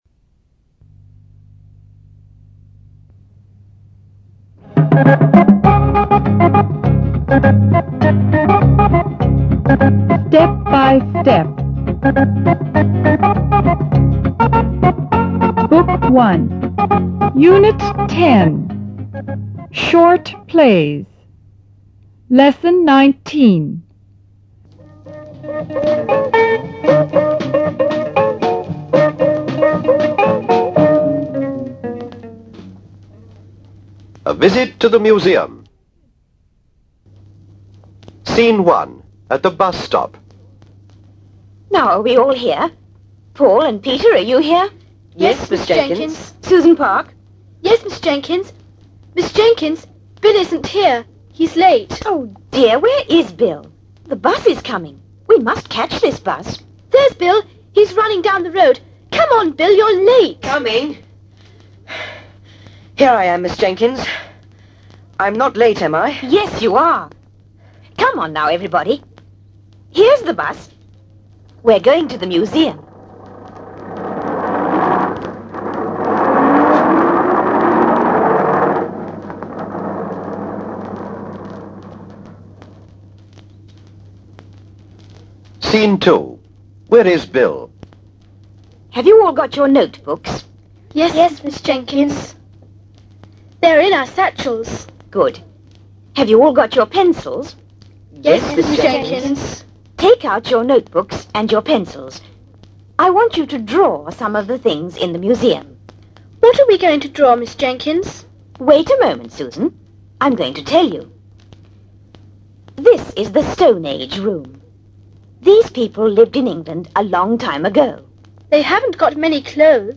UNIT 10 SHORTPLAYS Lesson 19 A Visit TO The Museum